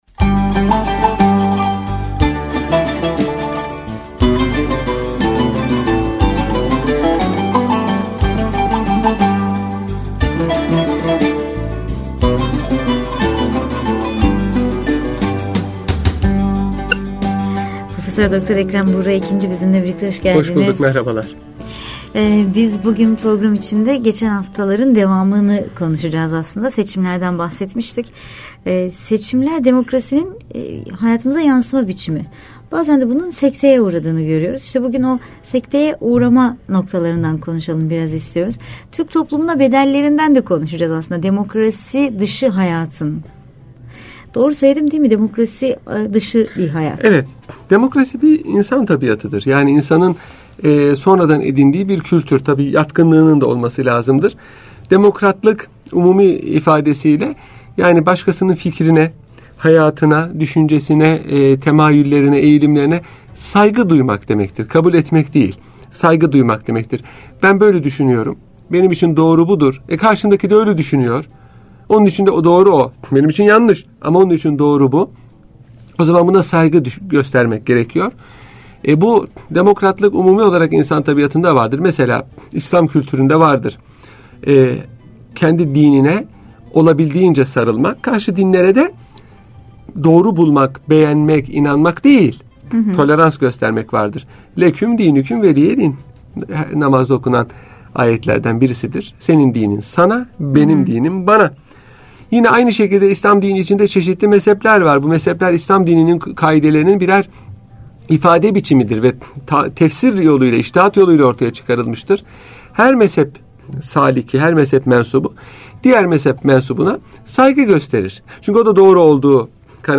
Radyo Programi - Askeri Darbeler